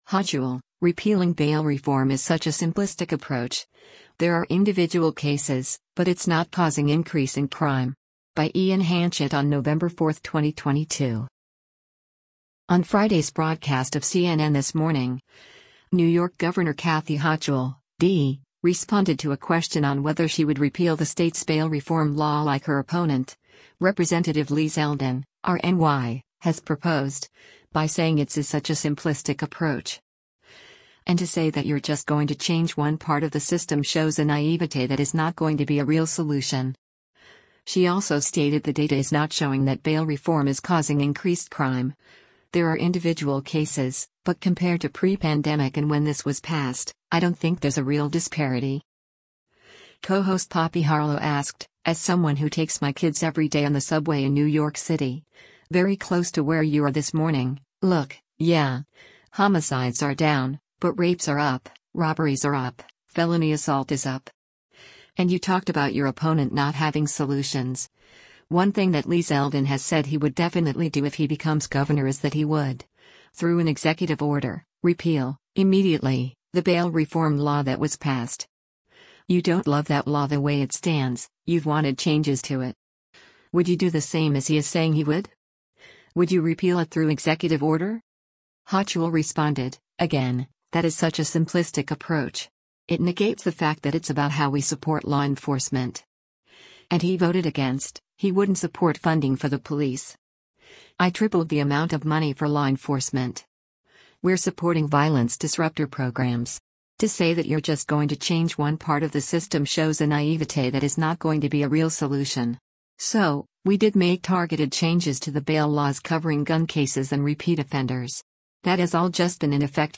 On Friday’s broadcast of “CNN This Morning,” New York Gov. Kathy Hochul (D) responded to a question on whether she would repeal the state’s bail reform law like her opponent, Rep. Lee Zeldin (R-NY), has proposed, by saying it’s “is such a simplistic approach.”